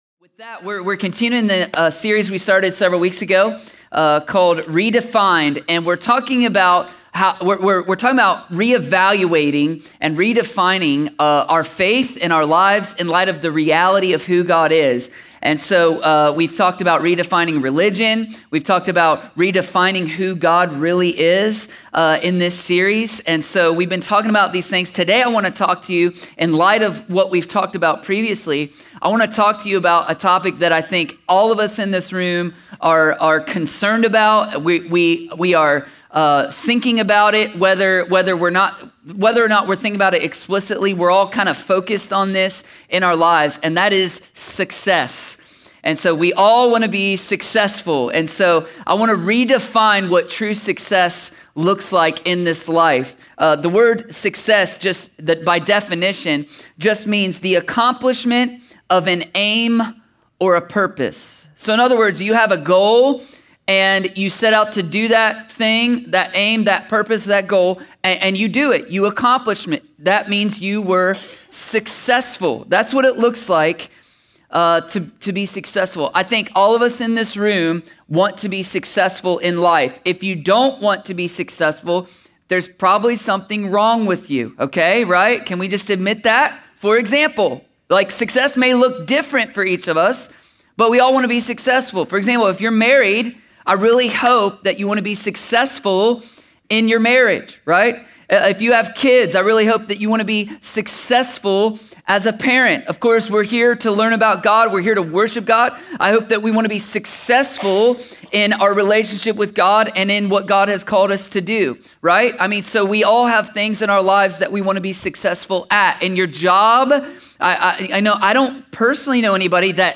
A sermon from the series “(RE)DEFINED.”…